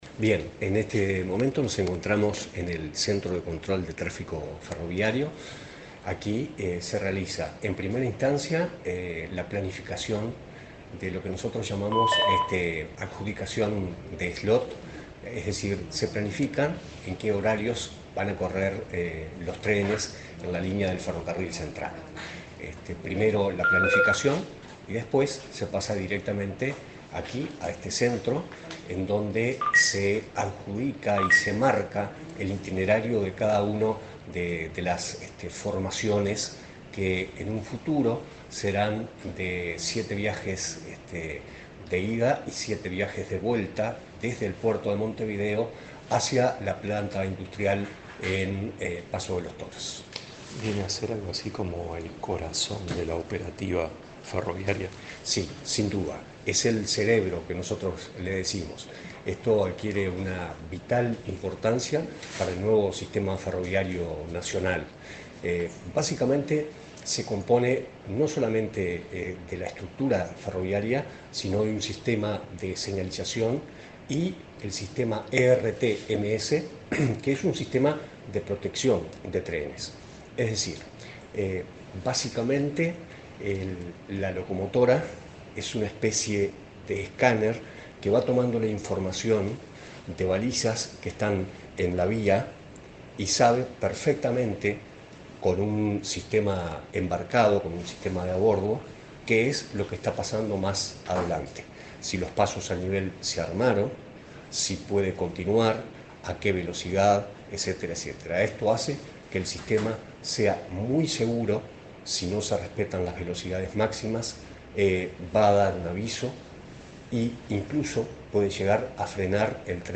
Entrevista al director nacional de Transporte Ferroviario
El director nacional de Transporte Ferroviario, Uruguay Graña, dialogó con Comunicación Presidencial, en el Centro de Operaciones del Ferrocarril